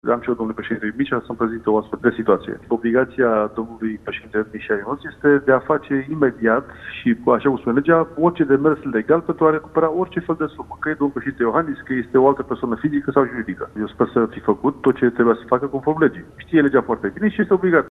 Imediat, ministrul Finanțelor, Eugen Teodorovici, a anunțat într-o intervenție la Antena 3 că tocmai a cerut Fiscului un raport despre ce a făcut pentru recuperarea acestor bani.